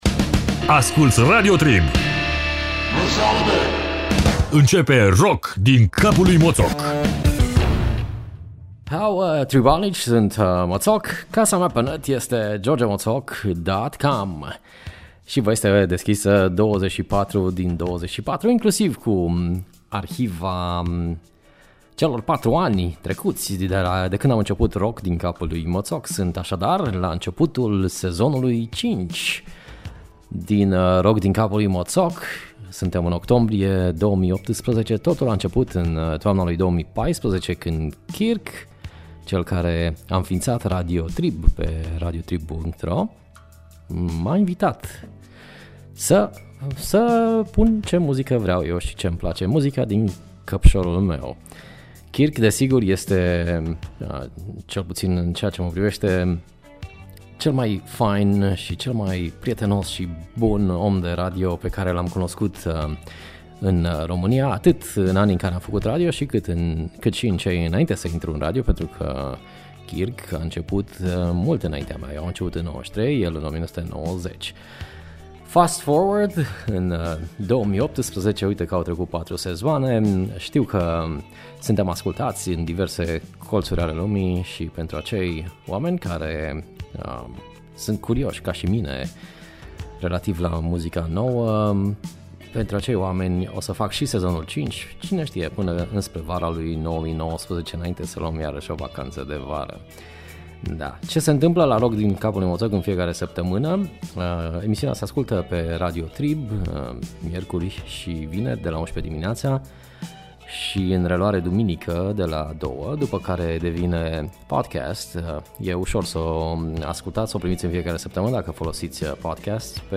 Fotbal, rock & roll si Southern Rock.